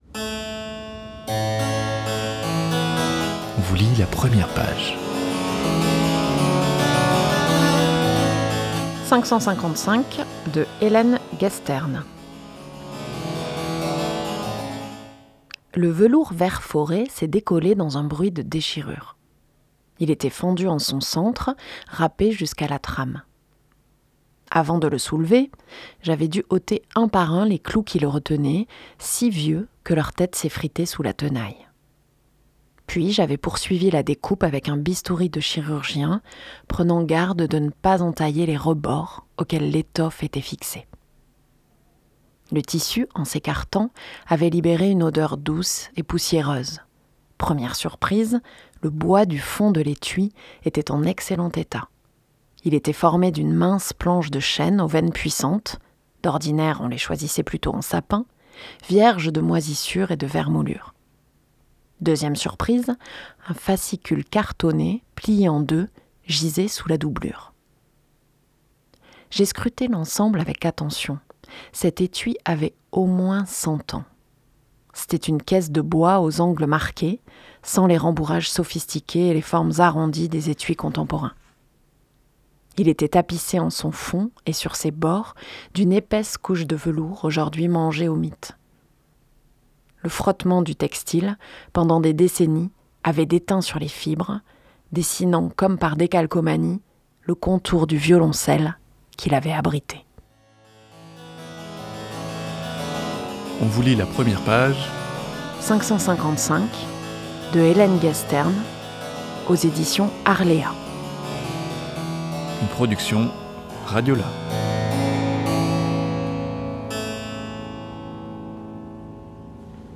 Dans l’émission Première page, RadioLà vous propose la lecture de l’incipit d’un roman.